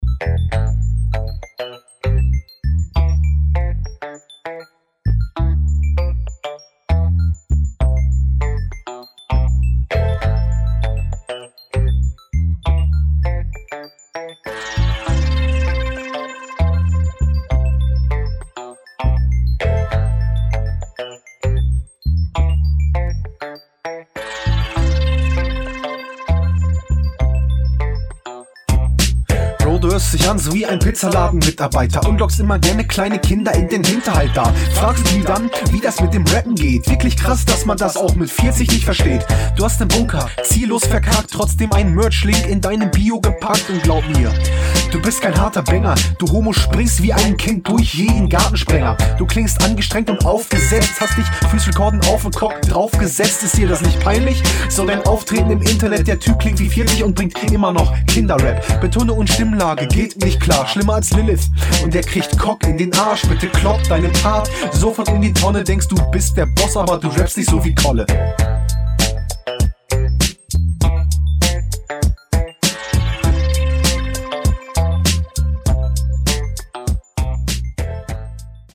Flow zu redig, aber generell lässig.